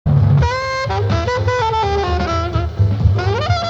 Howard Theater, Washington DC)より